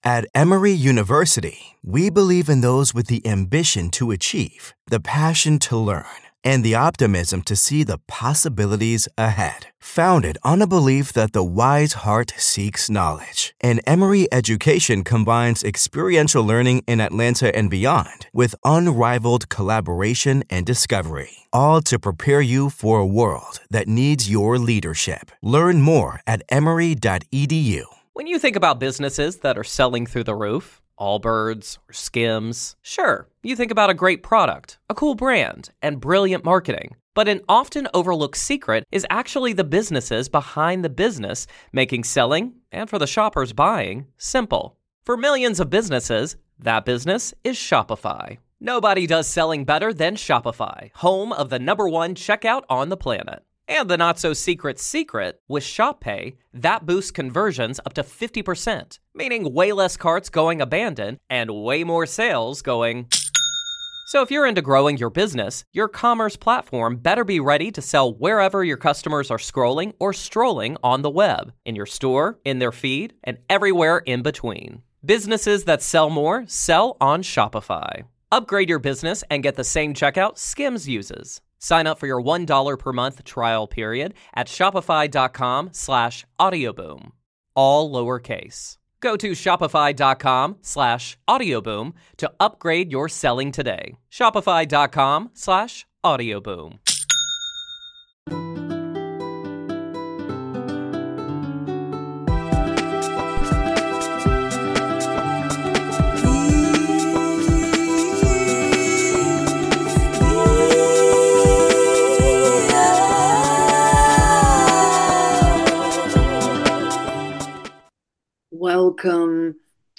Every show has awakening chats and interviews with incredible people from all around the world; light-workers, way-showers, truth speakers, earth keepers, love embracers, healers and therapists, and all those who are benefiting others and our planet in some way.